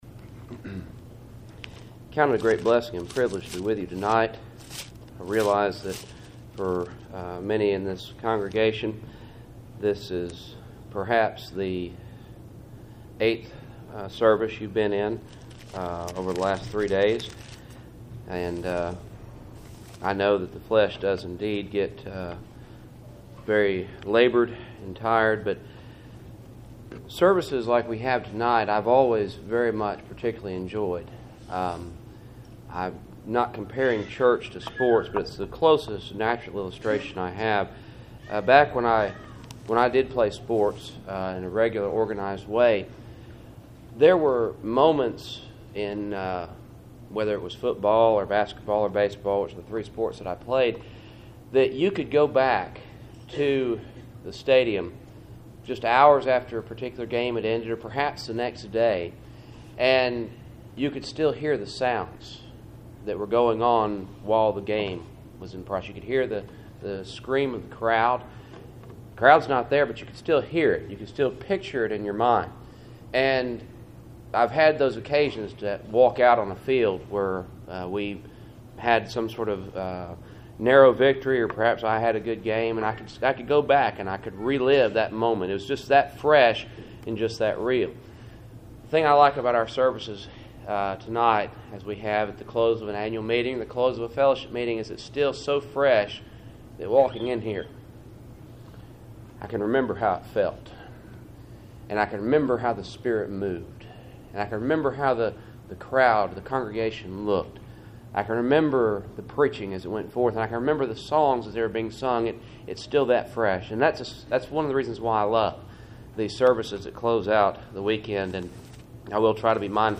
Service Type: Cool Springs PBC Sunday Evening